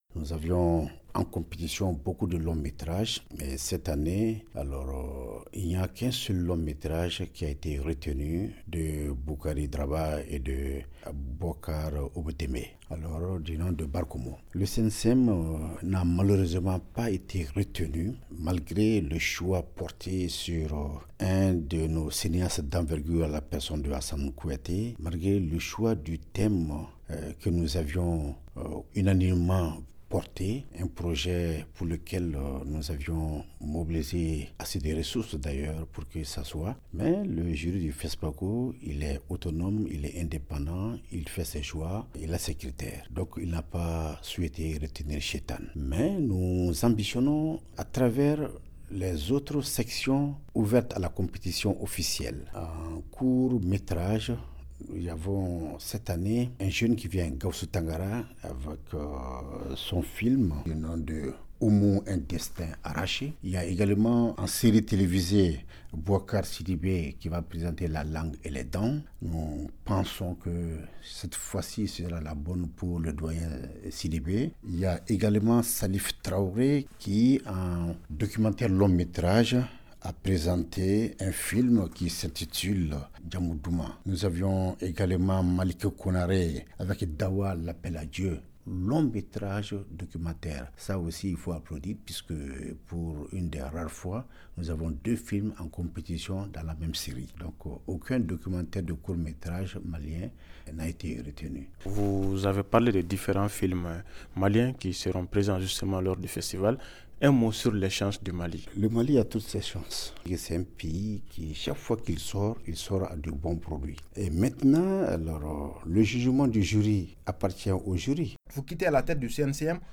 Quelques heures avant la passation de service avec le nouveau directeur, il a répondu aux questions